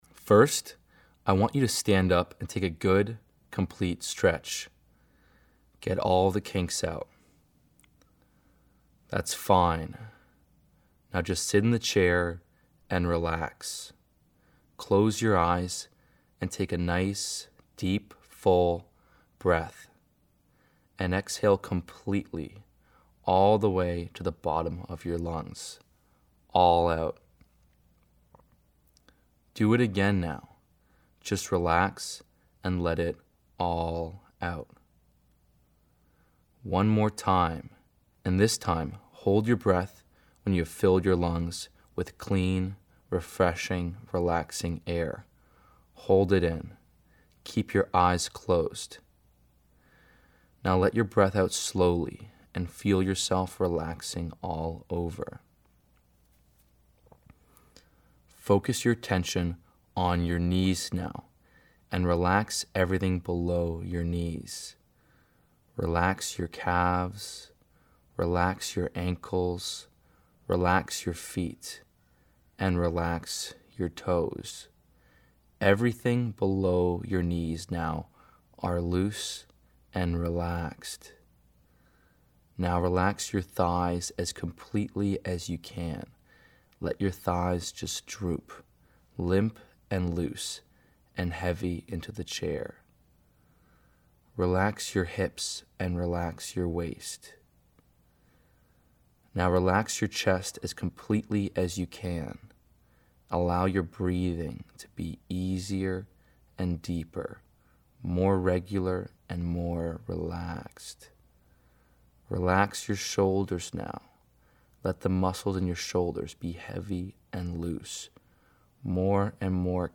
Self Hypnosis.mp3